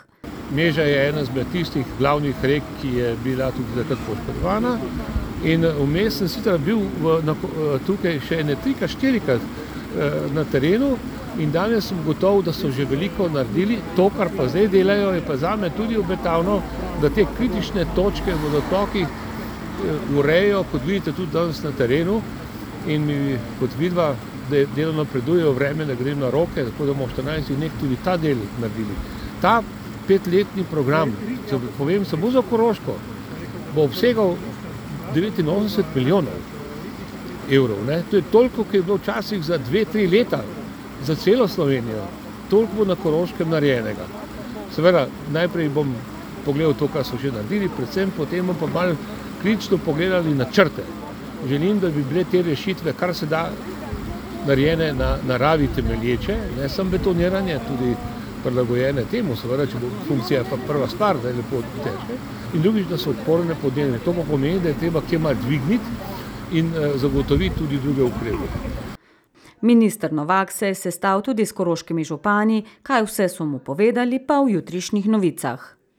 Kako dela potekajo v Mežiški dolini si je danes ogledal tudi minister za naravne vire in prostor Jože Novak z ekipo. In kakšne so prve ugotovitve s terena, minister Jože Novak: